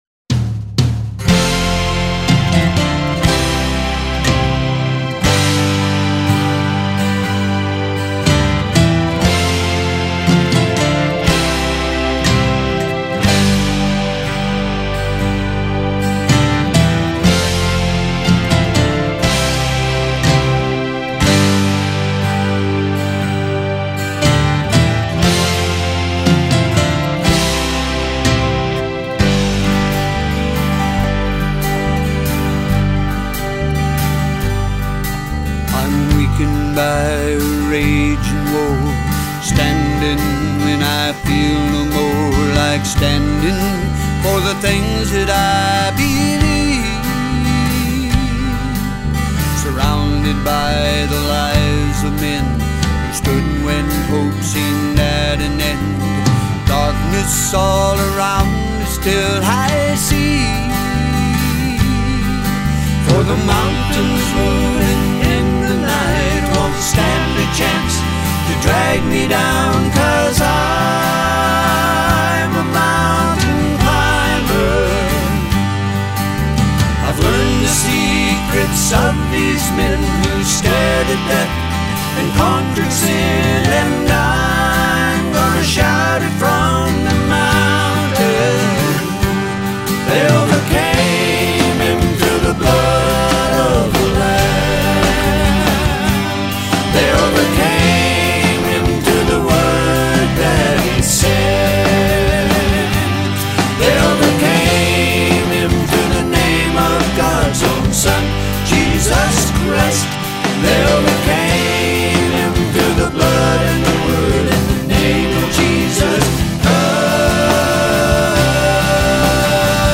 gospel singing brothers
keyboards